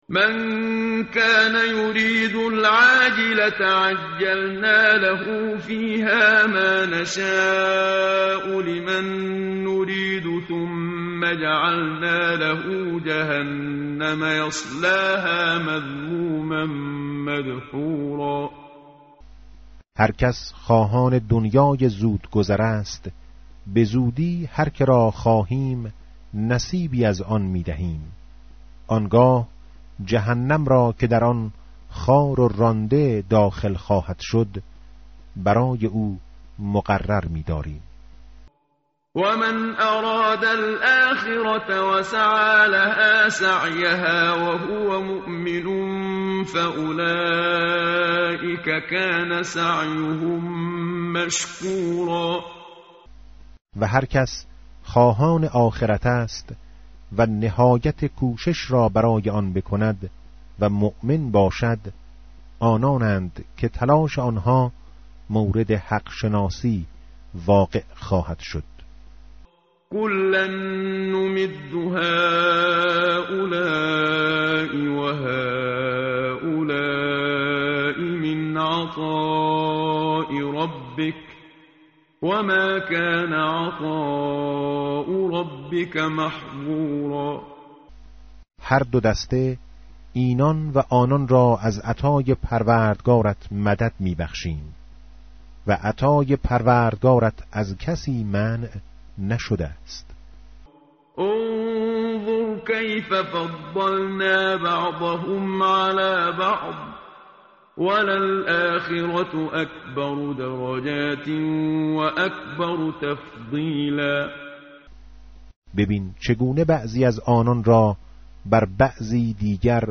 tartil_menshavi va tarjome_Page_284.mp3